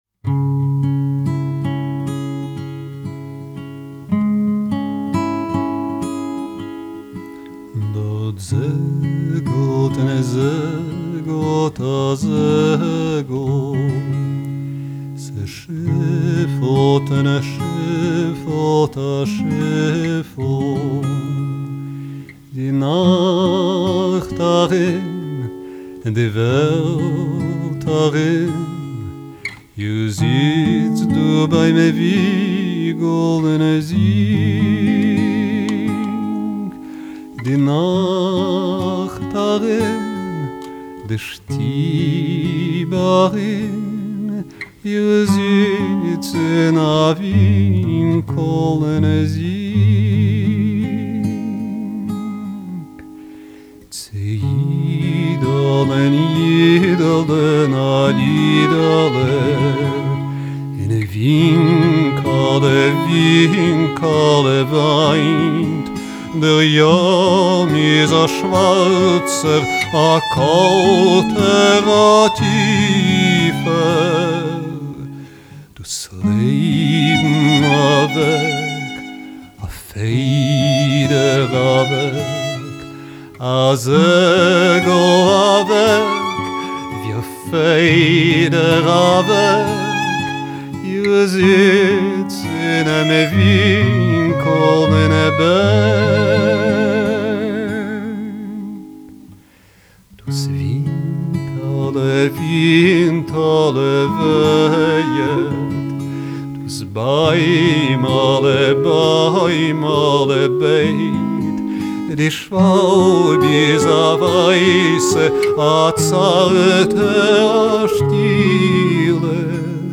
Canto judío.